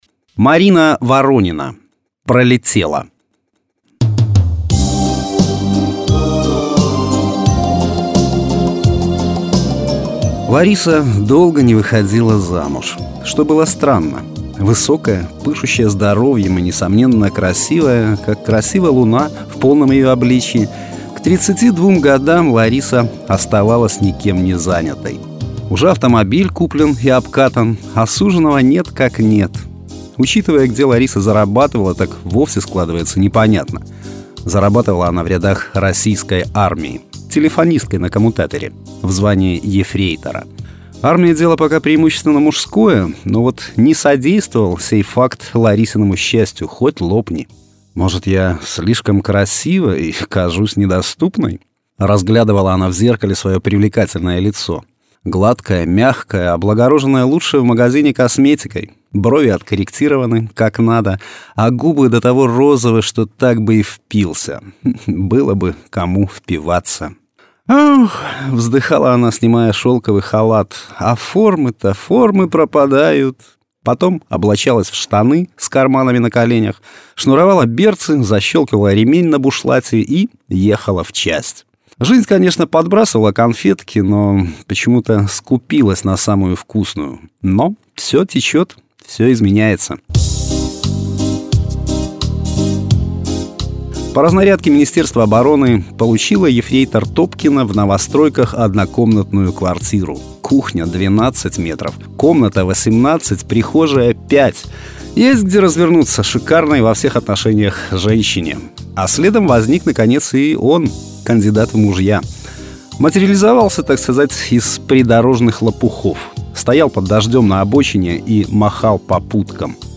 Аудиокниги
Качество: mp3, 256 kbps, 44100 kHz, Stereo